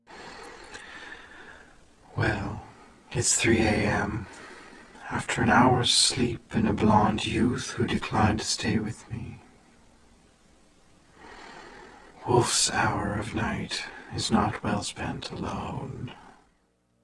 Source: "well it's 3am" (10:04-10:21)
Processing: Granulated, stretch 1:5, then 1:1 on "alone", grain dur = 25ms